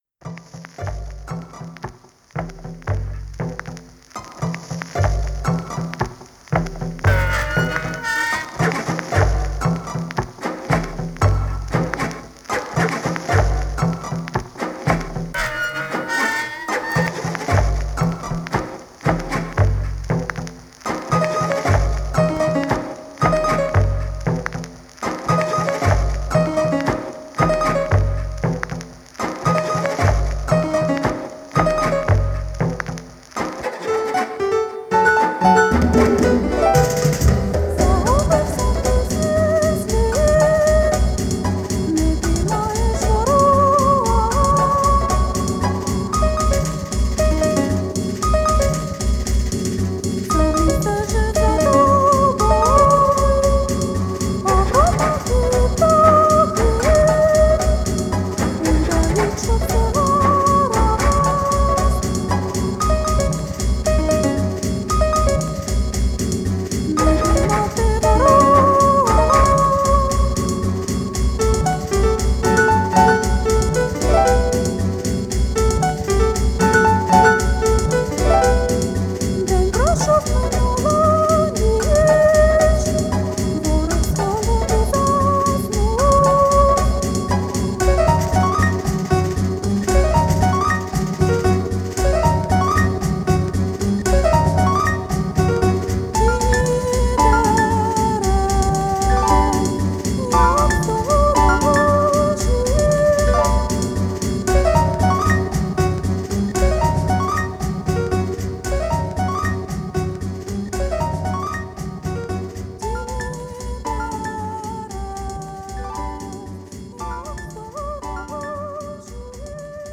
Electronix Wave Pop